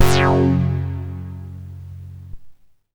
42-MASS LEAD.wav